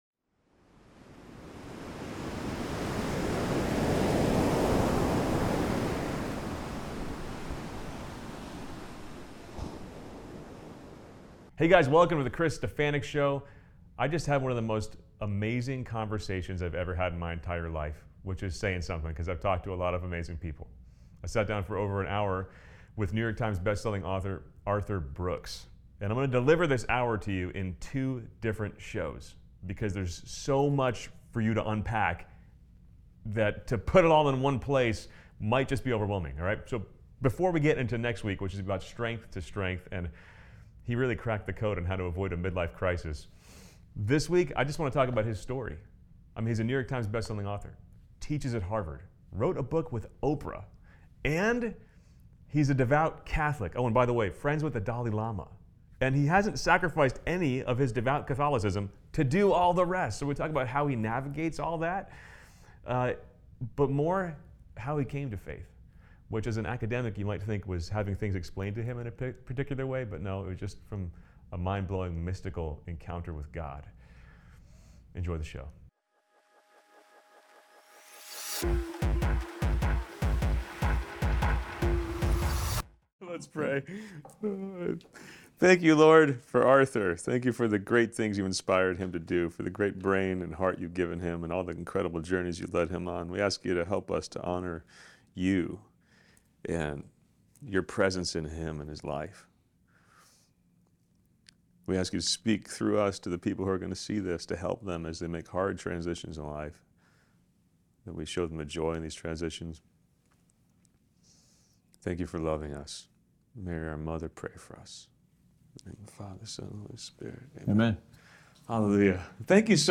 SPECIAL THANKS TO CORPORATE TRAVEL, PRODUCERS OF THE GOOD NEWS CONFERENCE for making this interview possible.